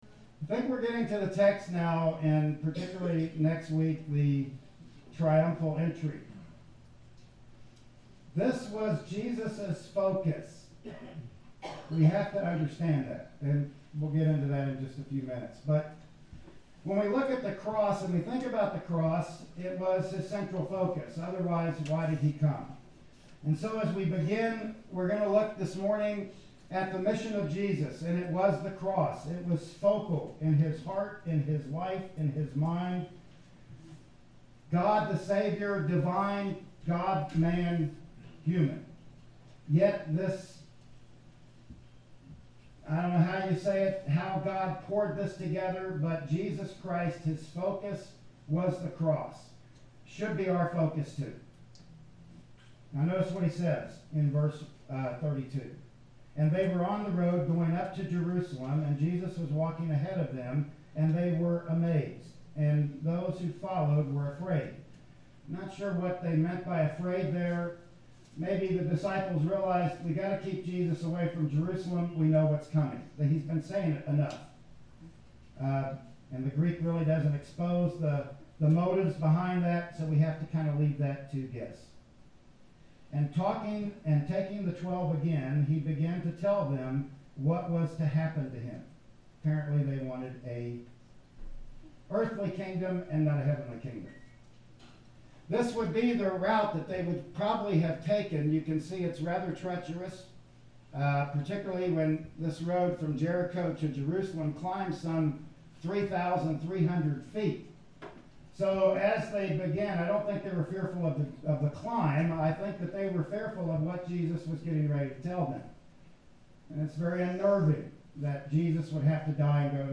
"Mark 10:32-52" Service Type: Sunday Morning Worship Service Bible Text